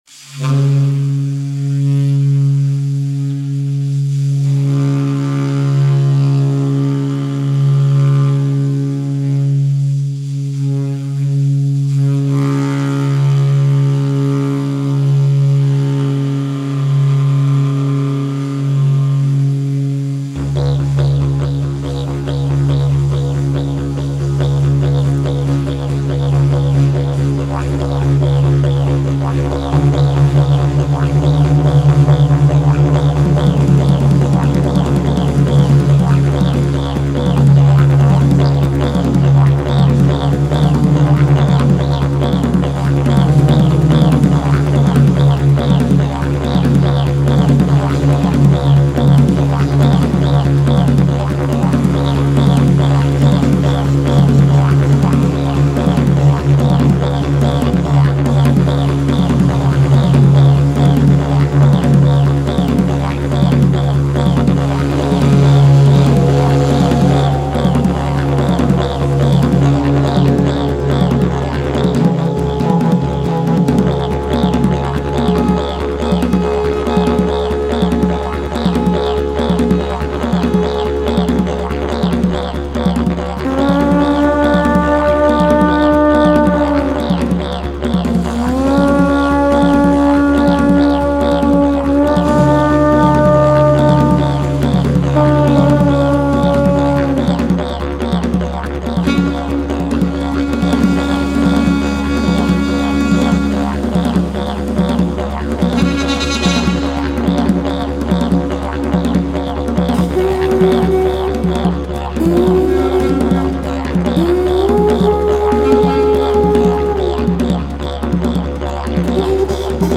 ( Didjeridoo, percussioni africane e conchighie).